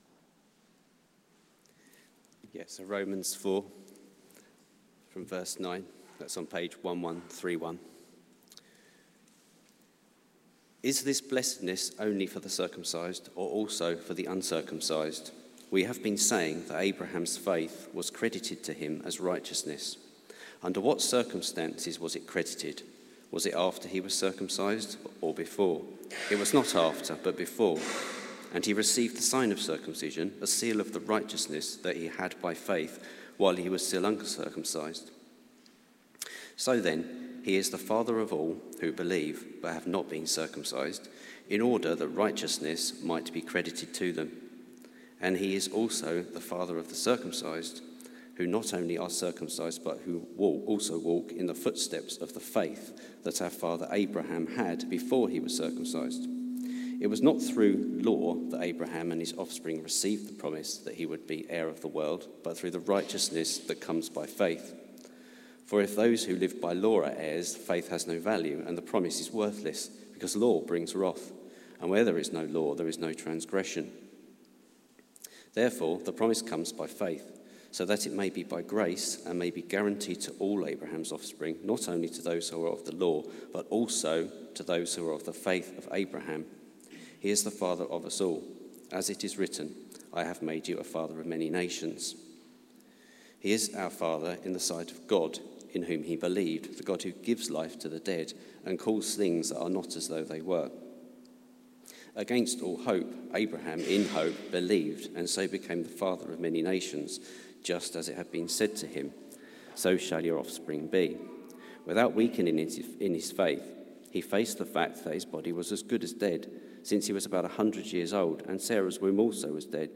Media for Service (10.45) on Sun 27th Nov 2022 10:45